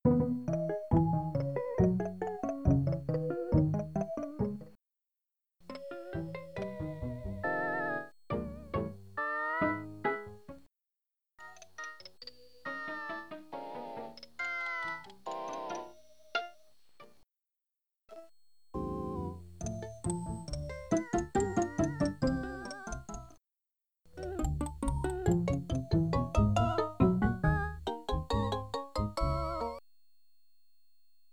Below is an isolated example of 0ms Delay Time. In the example, Main volume is set to 0, so only Echo is heard, and exactly one slider from the Filter setting is being raised & lowered at a time.
dryads_0ms_delay.mp3